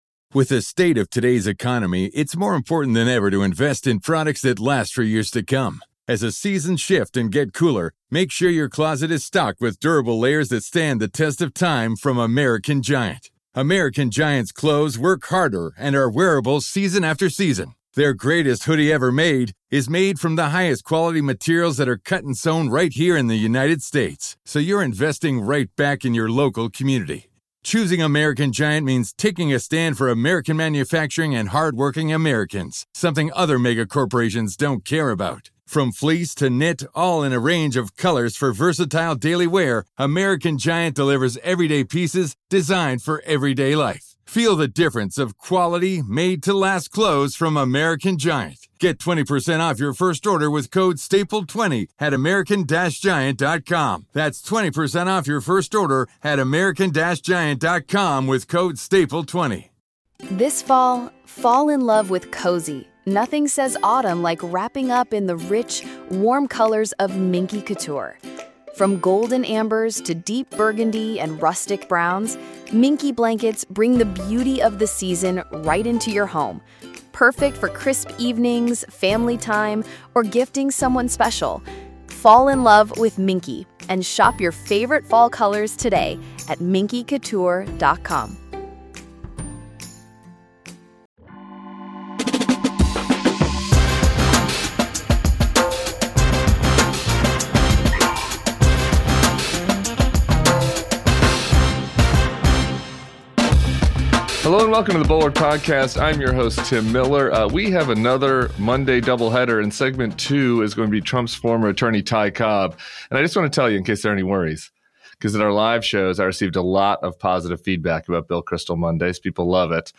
Former Trump attorney Ty Cobb and Bill Kristol join Tim Miller.